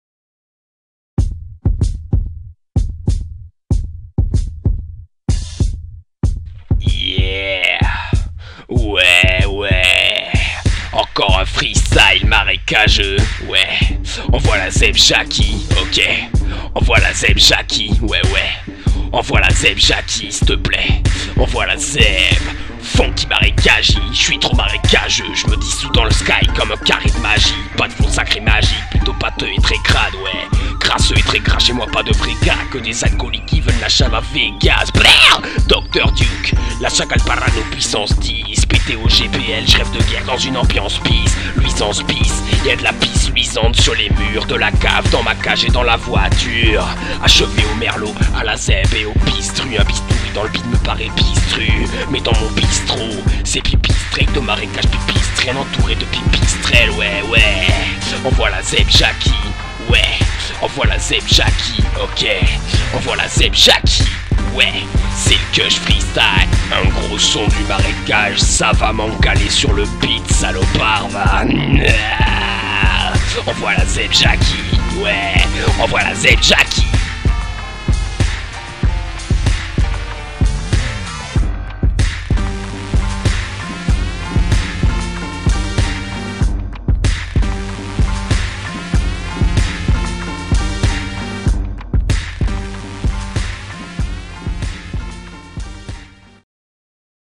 rap salace, crade, sombre, gore et malsain